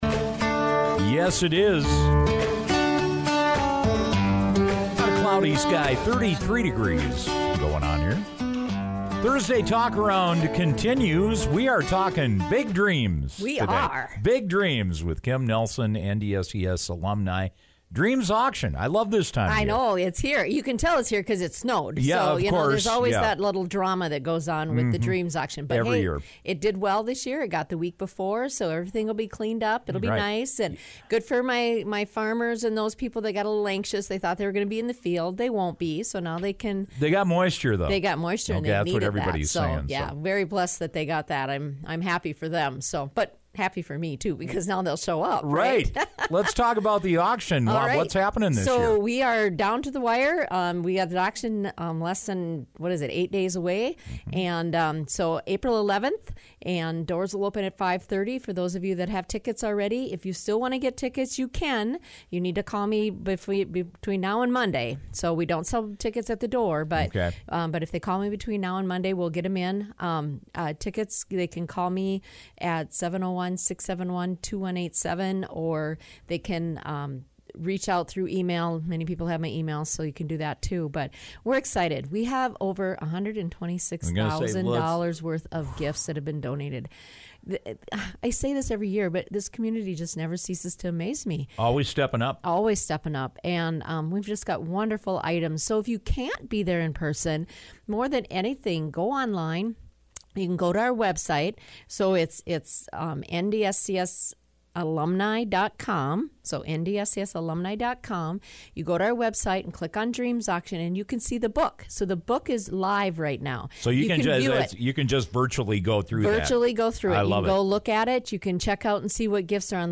It’s their biggest fundraiser of the year! NDSCS Alumni presents their Dreams Auction one week from tomorrow.